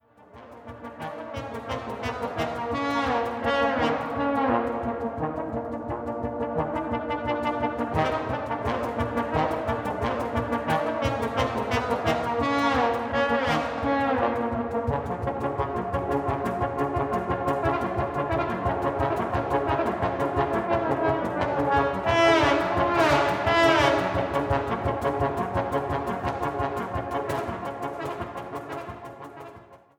Bassposaune
Percussion/Cajon
Der typische weiche Klang des Posaunenquartetts